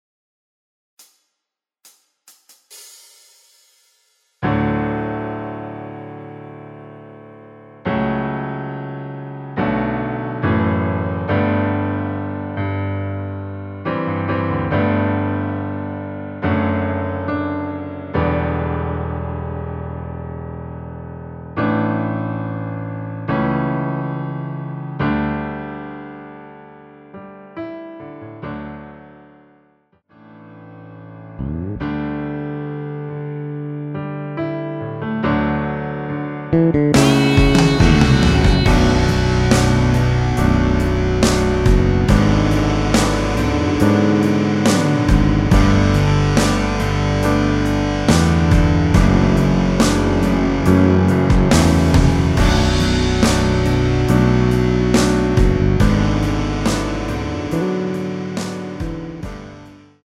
MR 입니다.
전주없이 노래가시작되는곡이라 카운트 만들어 놓았습니다.
◈ 곡명 옆 (-1)은 반음 내림, (+1)은 반음 올림 입니다.
앞부분30초, 뒷부분30초씩 편집해서 올려 드리고 있습니다.
중간에 음이 끈어지고 다시 나오는 이유는